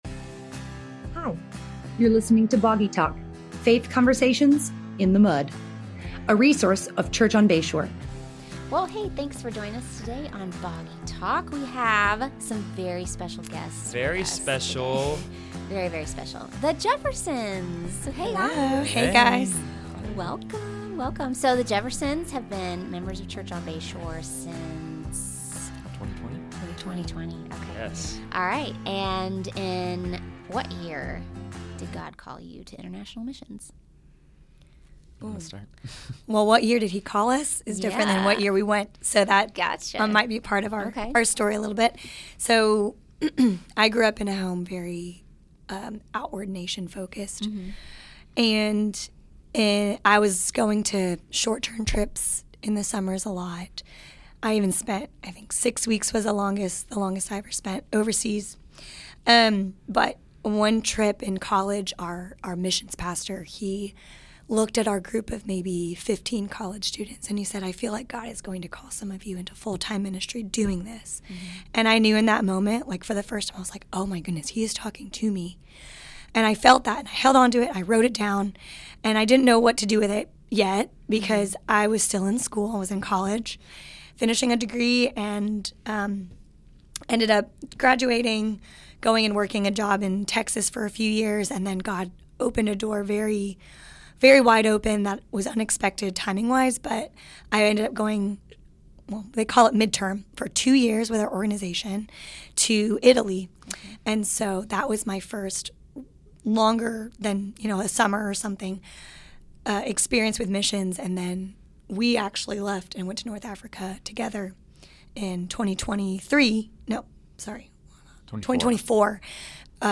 global workers living and serving in North Africa, on cross-cultural living, missions engagement, and how God is it at work where they serve.<